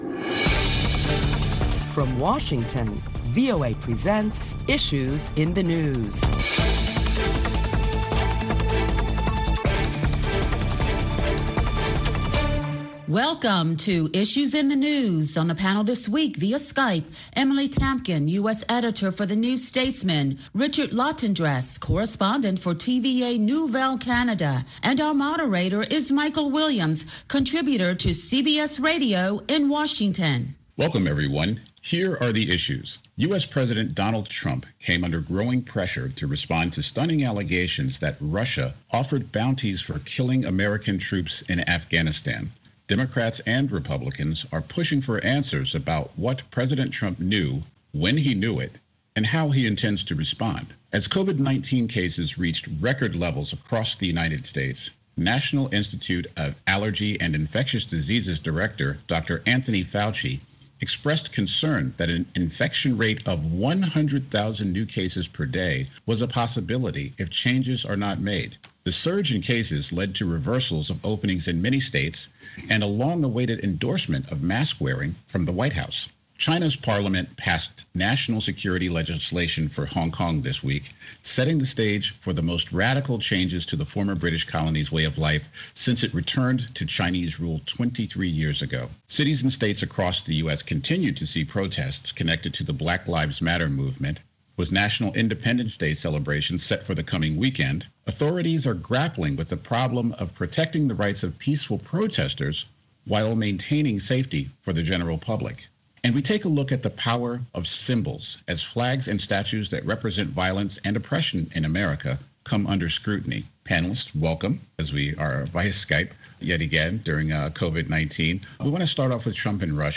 A panel of prominent Washington journalists deliberate the latest top stories of the week including the growing pressure for President Trump to respond to allegations that Russia offered bounties for killing American troops in Afghanistan.